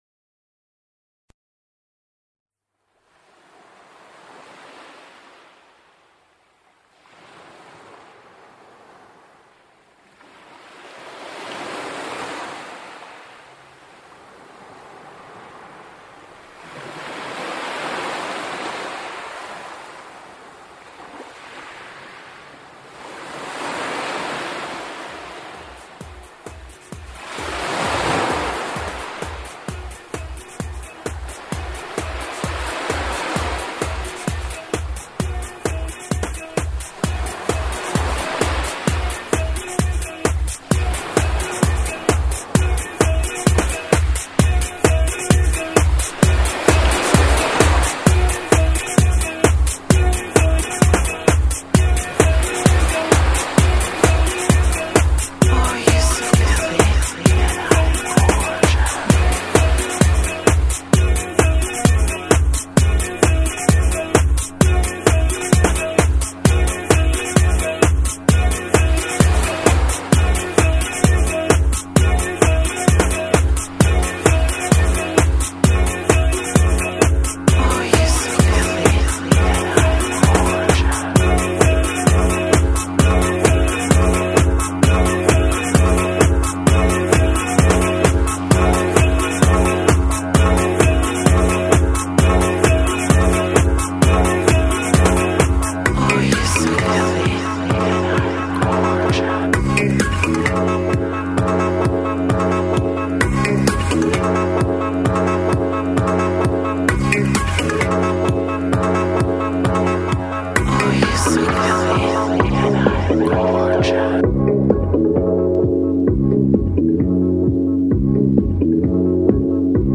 french house